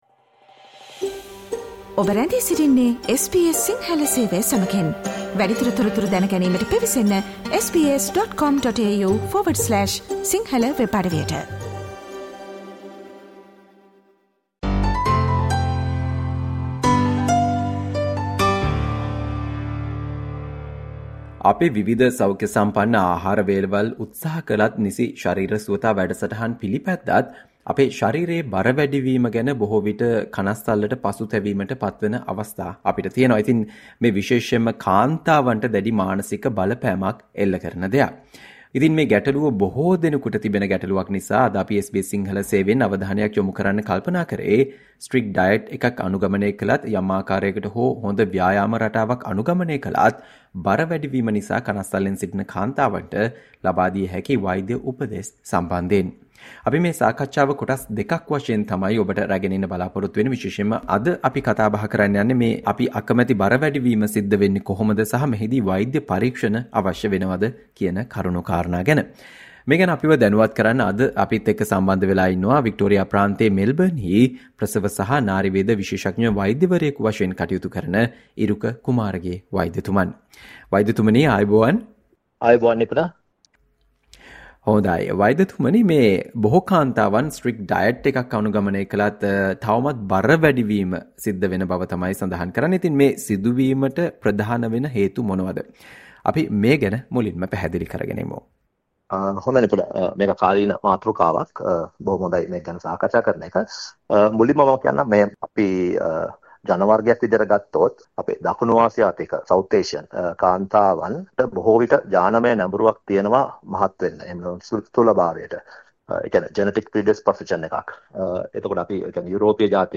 සෞඛ්‍යසම්පන්න අහාර වේලවල් උත්සහ කලත් නිසි ශරීර සුවතා වැඩසටහන් පිලිපැද්දත් ශරීරයේ බර වැඩි වීම පිළිබඳව කනස්සල්ලෙන් සිටින කාන්තාවන් දැනගත යුතු කරුණු සම්බන්ධයෙන් SBS සිංහල සේවය සිදු කල සාකච්ඡා මාලාවේ පළමු සාකච්ඡාවට සවන් දෙන්න